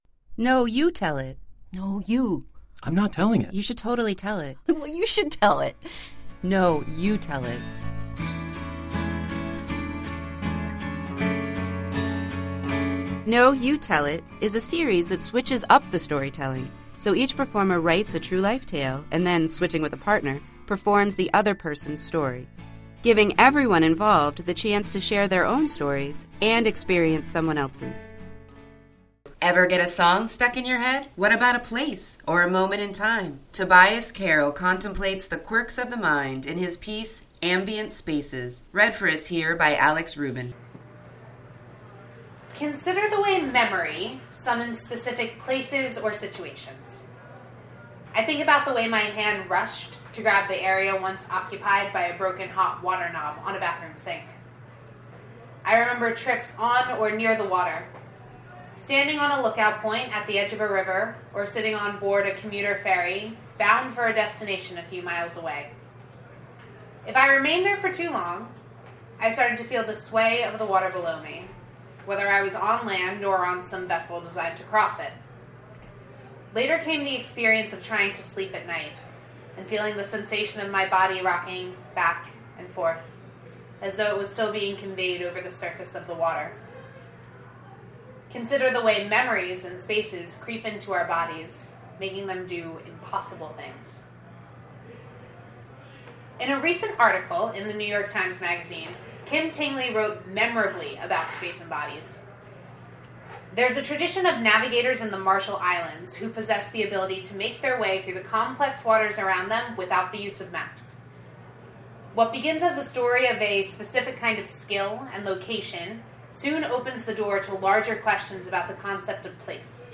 Read for us here